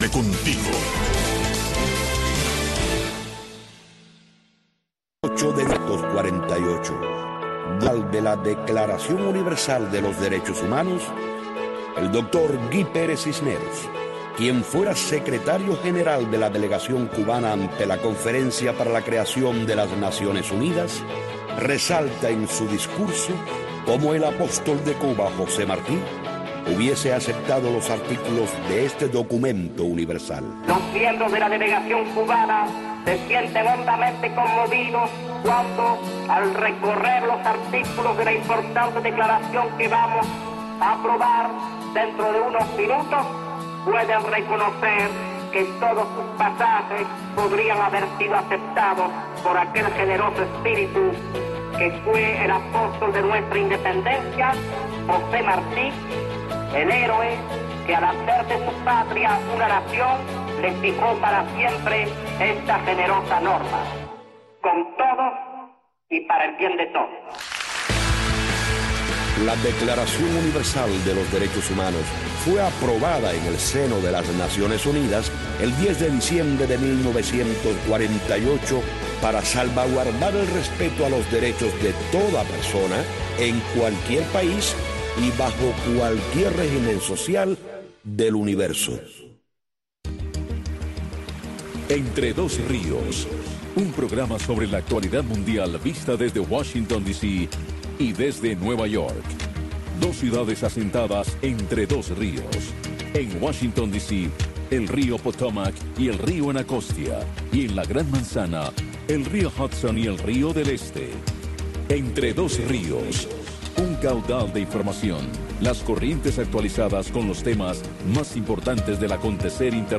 Radio Martí les ofrece una revista de entrevistas